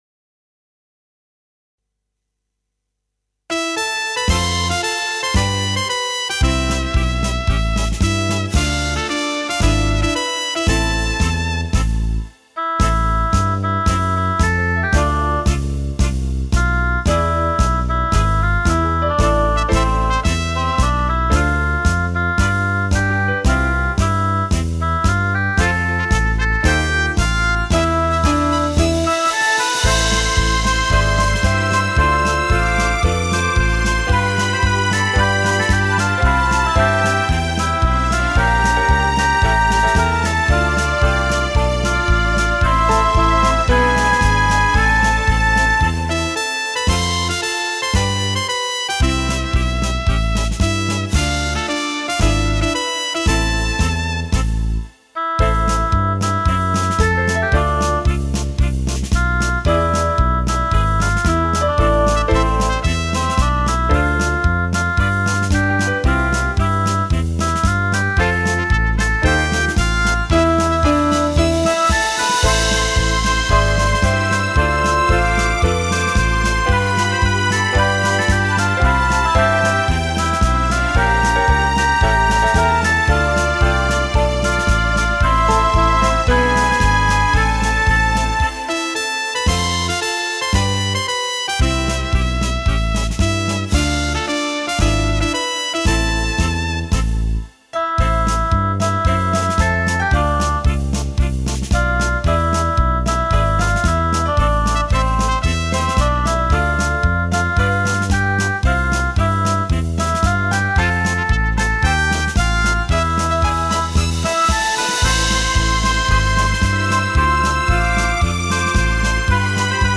Слушать минус